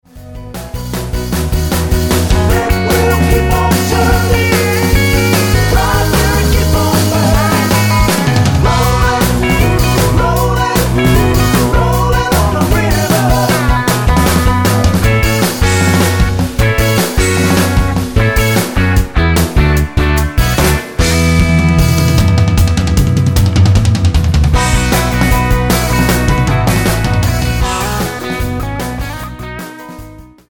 --> MP3 Demo abspielen...
Tonart:B-C-Db-D mit Chor